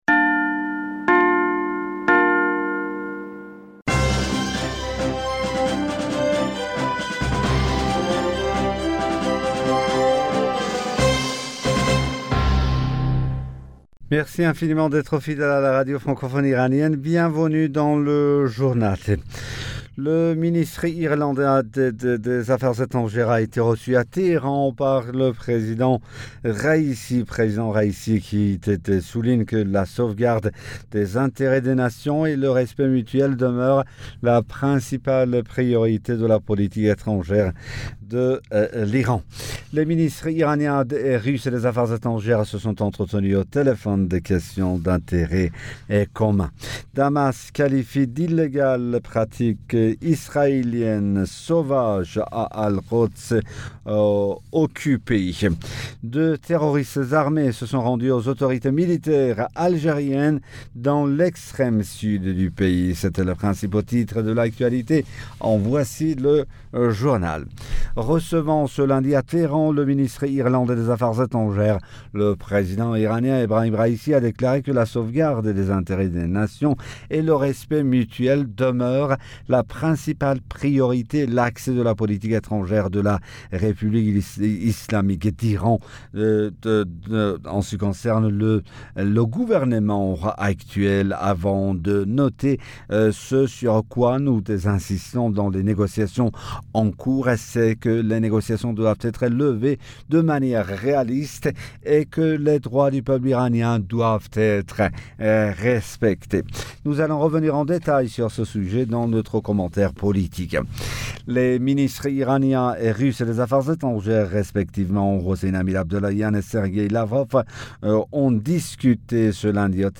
Bulletin d'information Du 15 Fevrier 2022